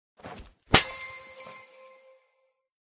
Волшебный хлопок